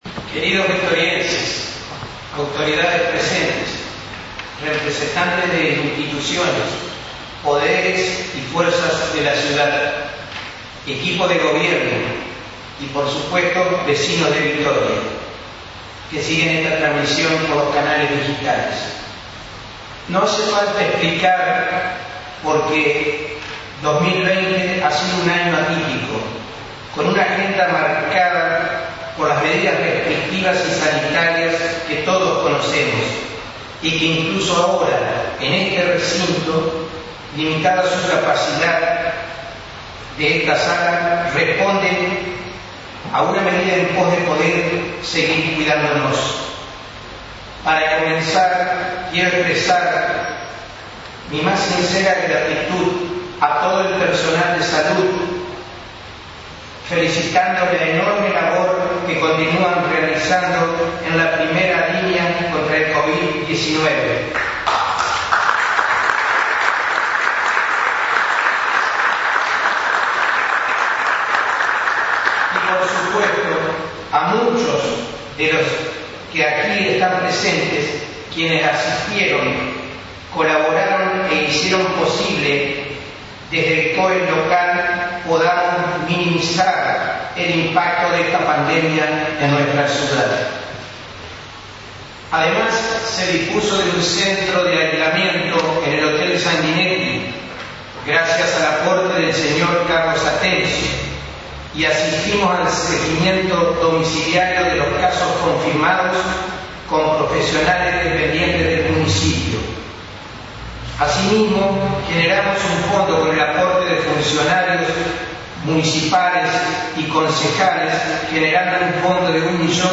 El Intendente Domingo Maiocco dio su discurso anual en el inicio de Sesiones Ordinarias en el Concejo Deliberante – Lt39 Noticias
discurso-maiocco.mp3